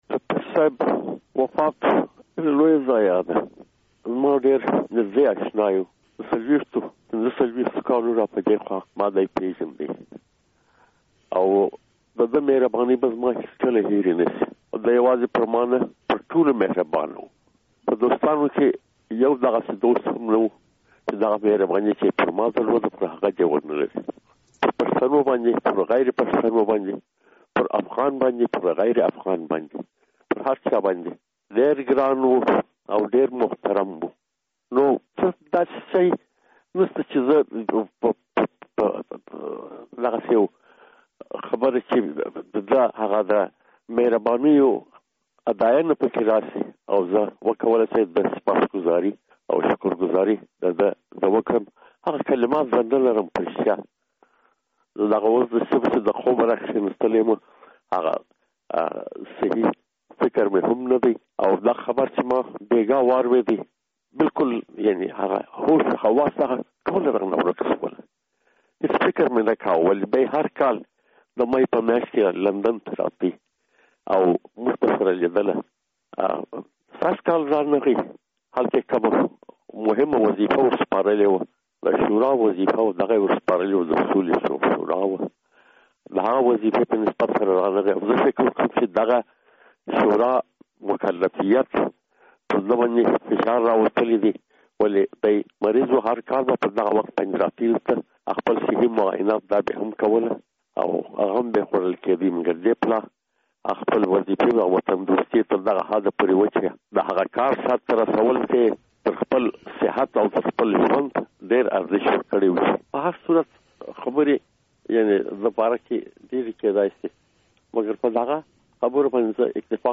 مرکه
له استاد ناشناس سره مرکه